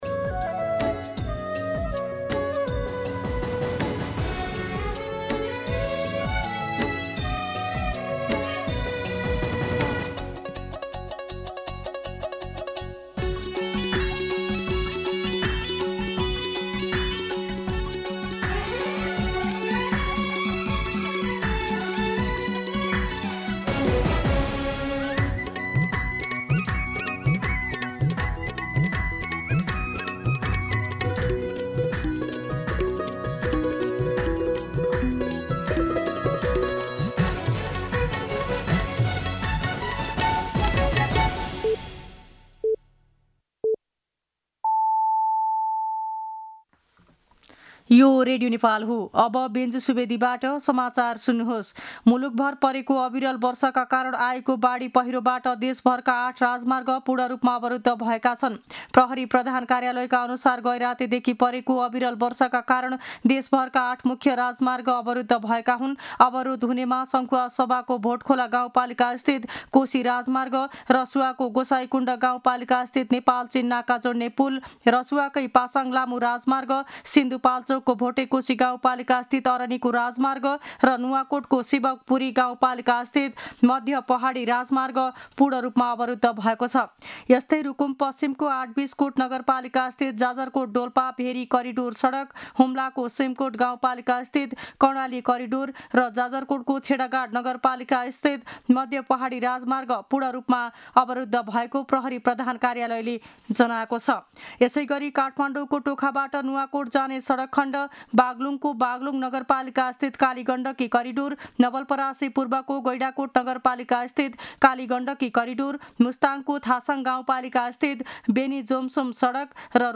मध्यान्ह १२ बजेको नेपाली समाचार : १२ साउन , २०८२
12-pm-Nepali-News-4.mp3